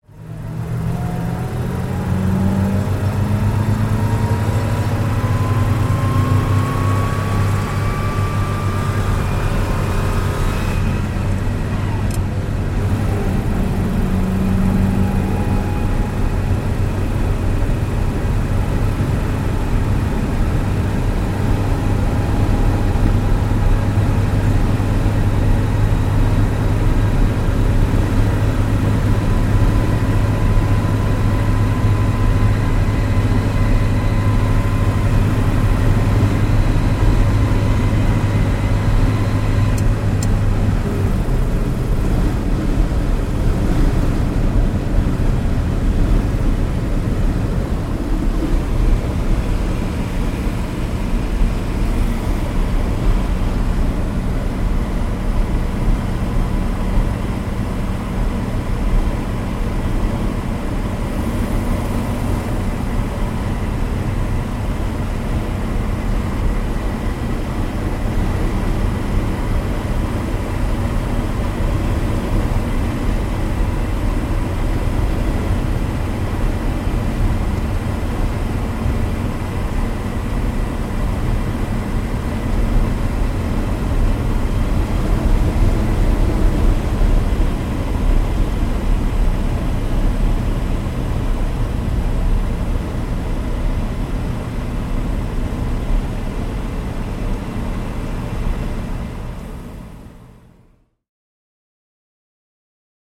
Атмосферные звуки движения в кабине эвакуатора